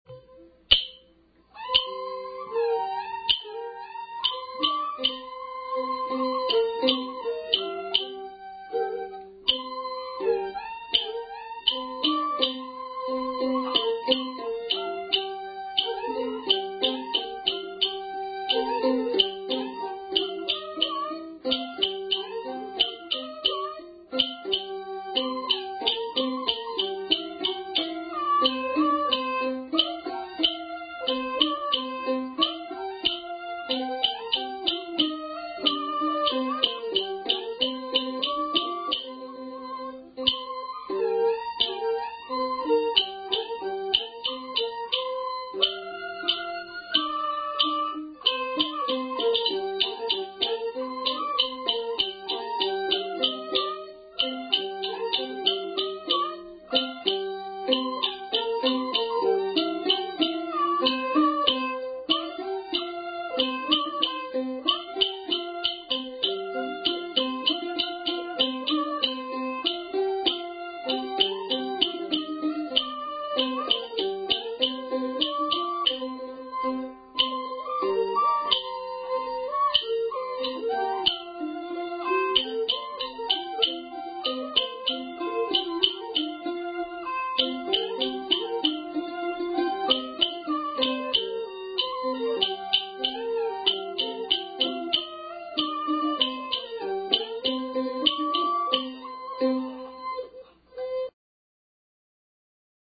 這裡收錄民間藝人的採集樣品，有歌仔調，有部分北管戲曲，也有通俗音樂（八音譜）等，都是鄉土原味。